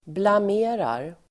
Uttal: [blam'e:rar]